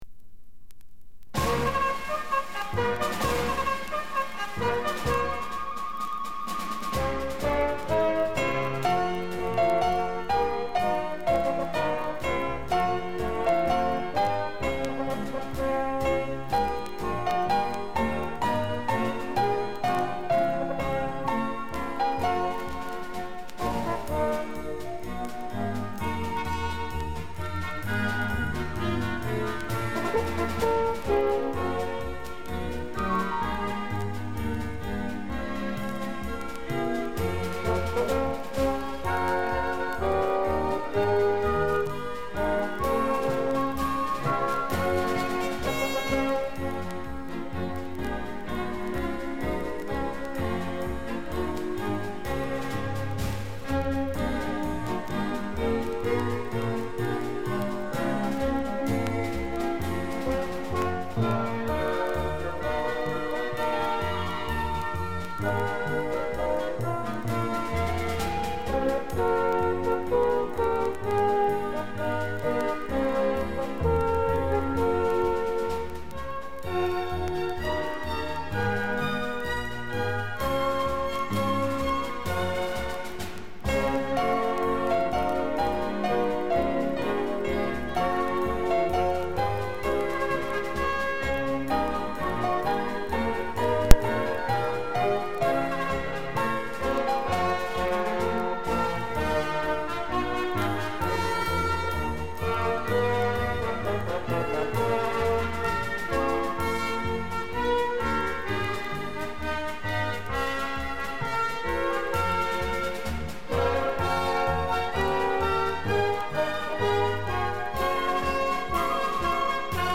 Описание: Без слов-любителям караоке